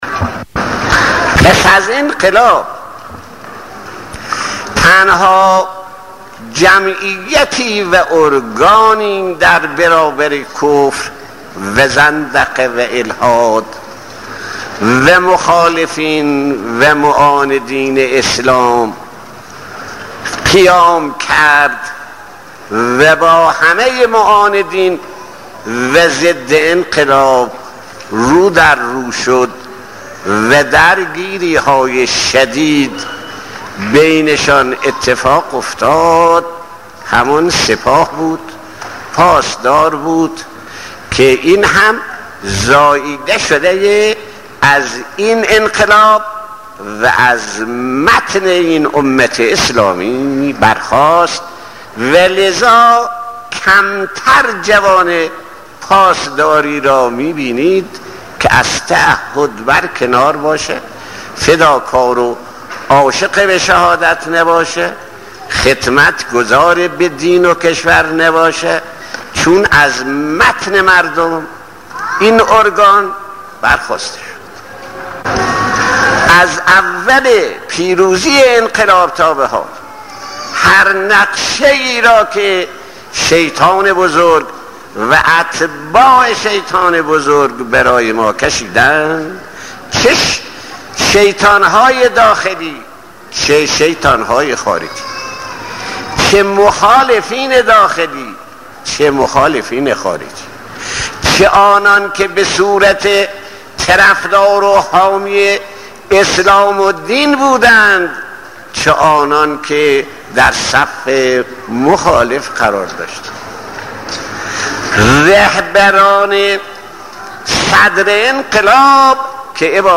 صوت/ سخناني از شهيد آيت الله صدوقى پيرامون نقش سپاه پاسدران در انقلاب اسلامي